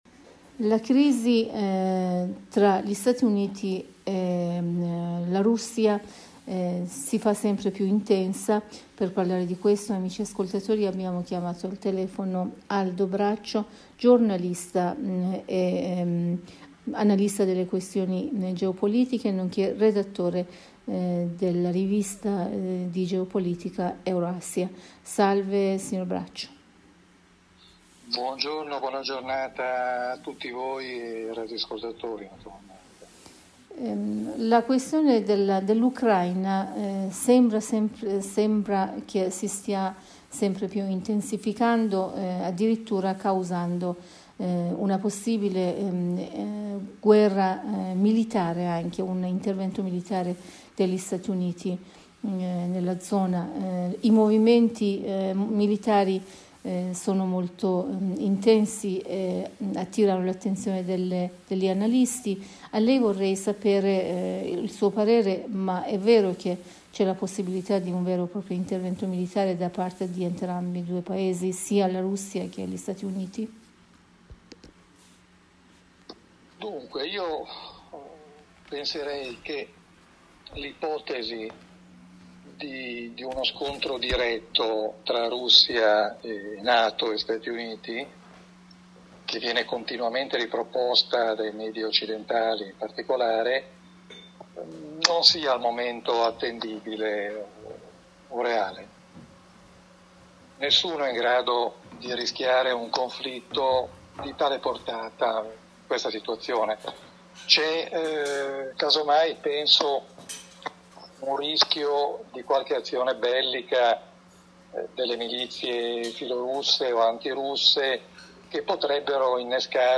in un collegamento telefonico con la Voce della Repubblica islamica dell'Iran (IRIB)